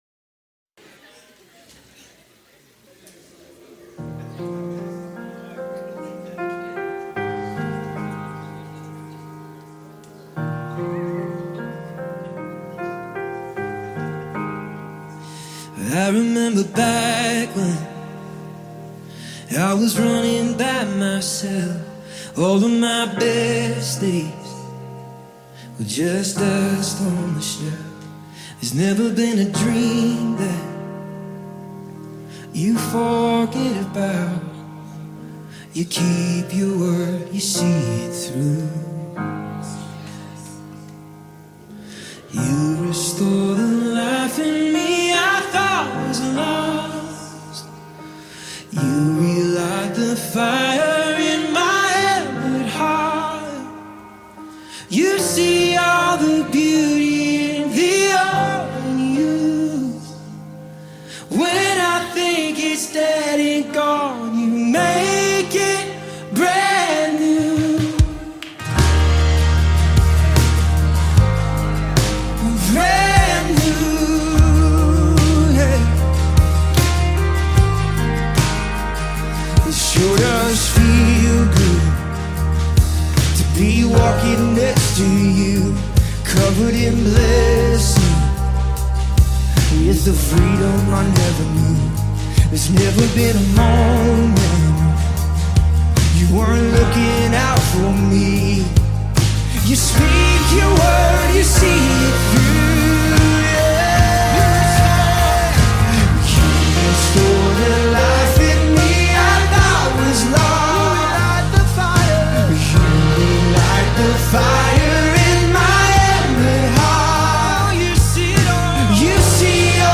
beautiful soul-lifting worship song